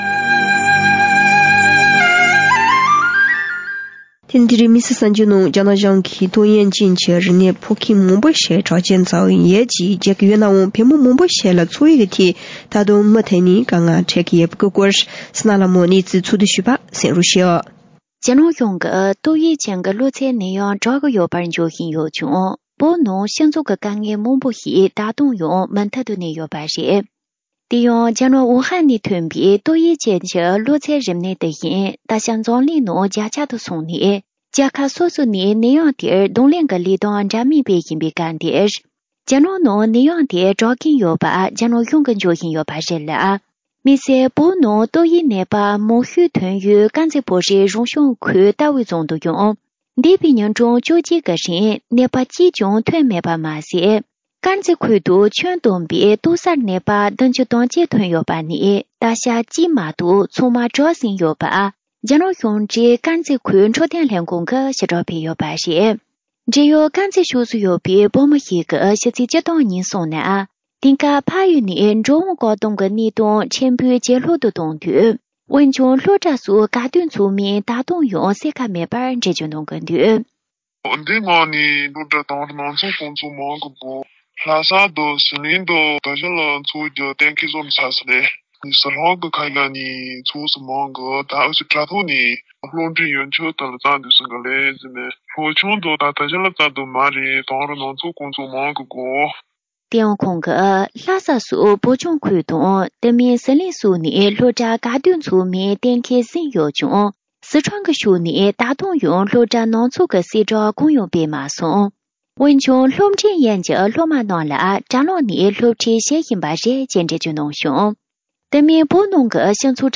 གནས་ཚུལ་ཕྱོགས་བསྡུས་ཞུས་པར་གསན་རོགས།